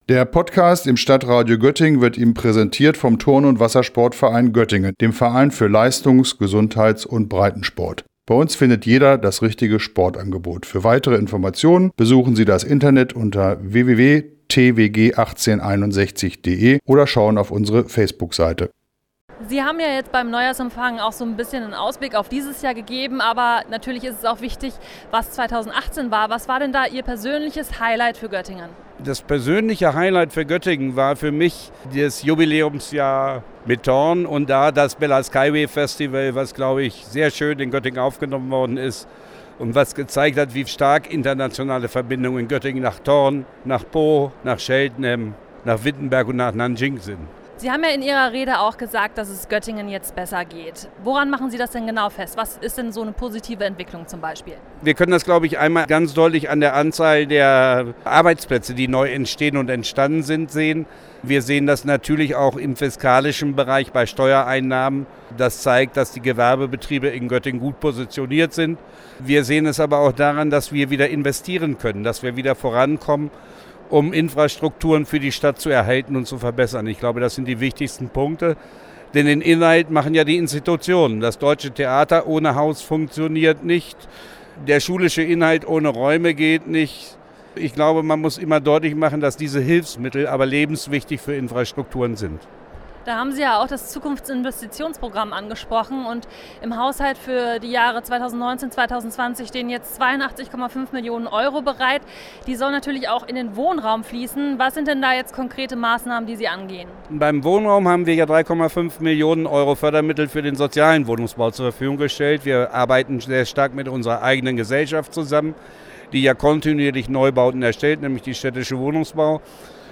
Gestern lud dann die Stadt Göttingen zum Einklang in das neue Jahr in die Lokhalle – zum ersten Mal, da in der Vergangenheit das neue Jahr immer in der Stadthalle begrüßt worden war.
Mehrere hundert Besucher lauschten daher in der Lokhalle der Neujahrsrede von Oberbürgermeister Rolf-Georg Köhler.
Oberbürgermeister Rolf-Georg Köhler bei seiner Rede auf dem Neujahrsempfang der Stadt Göttingen 2019.